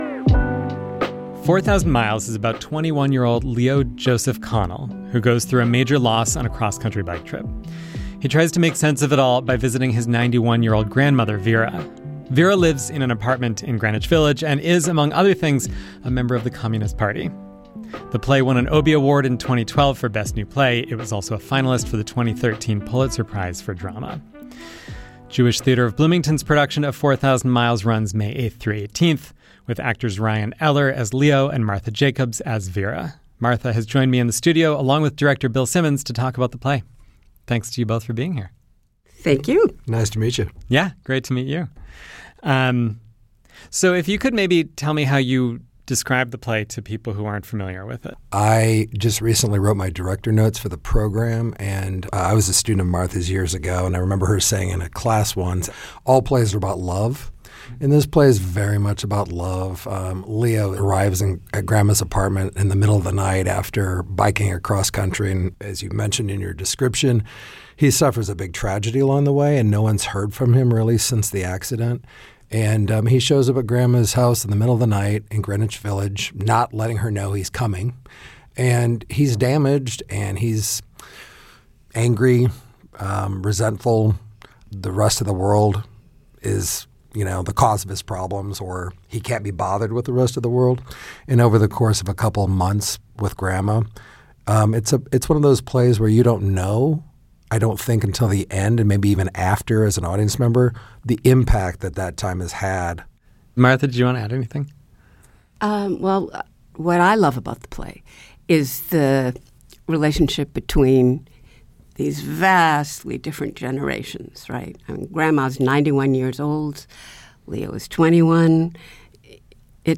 Arts and culture news, events, interviews, and features from around southern and central Indiana.